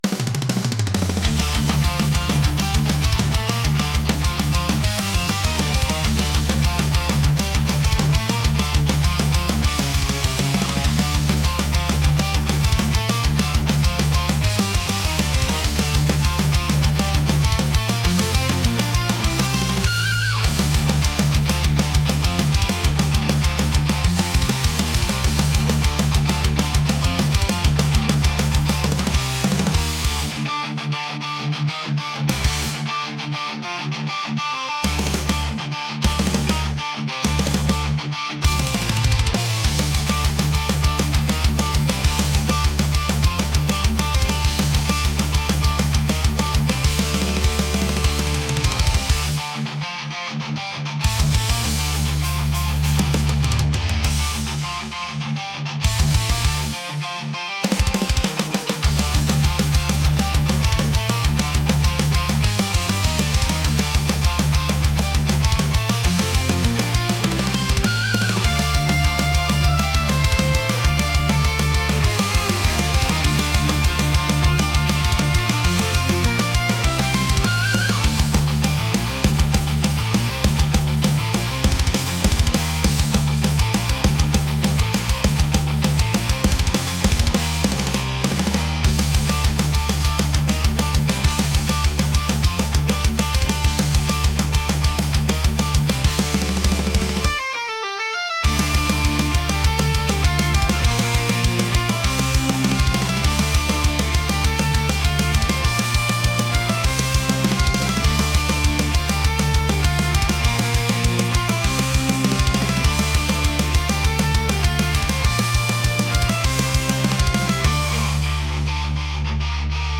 aggressive | metal